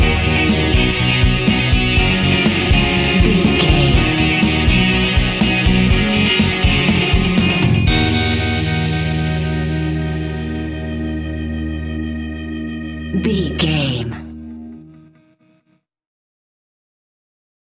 Ionian/Major
E♭
pop rock
fun
energetic
uplifting
indie music
electric guitar
Distorted Guitar
Overdrive Guitar
Rock Bass
Rock Drums
hammond organ